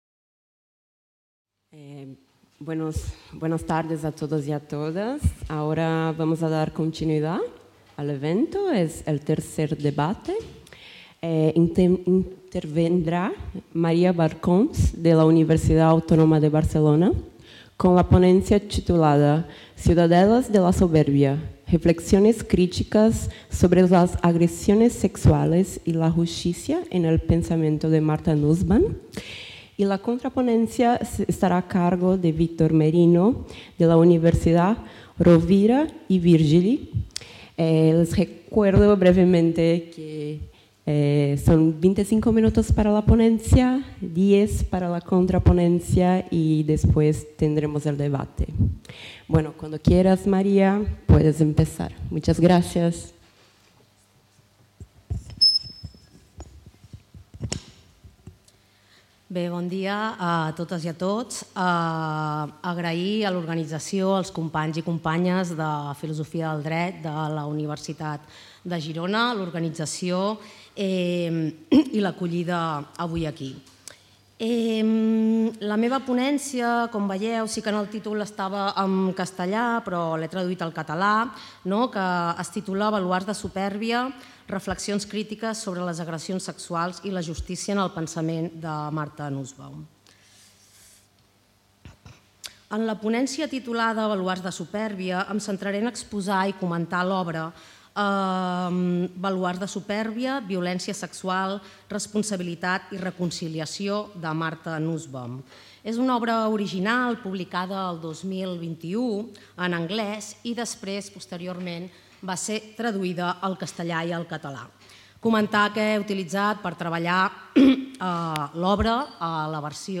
The Chair of Legal Culture of the UdG organizes the II Catalan Interuniversity Seminar on Philosophy of Law.